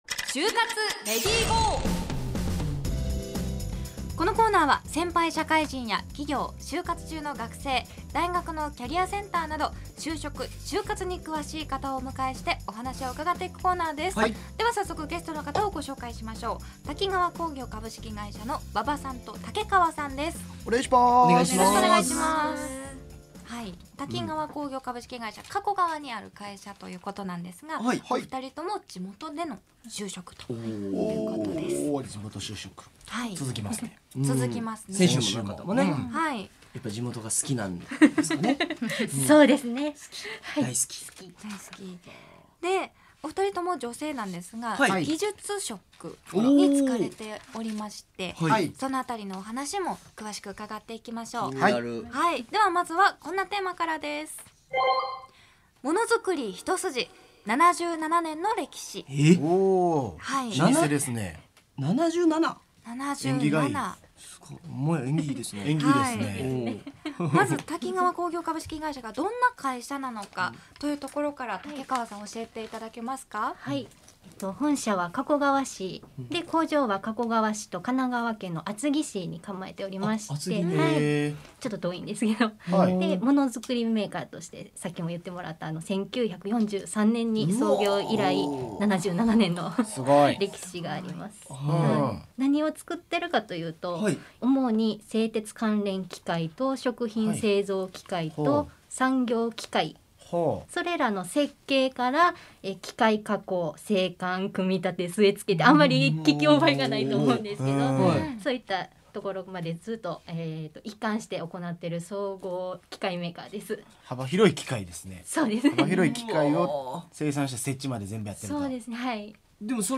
『ネイビーズアフロのレディGO！HYOGO』2020年12月4日放送回（「就活レディGO！」音声）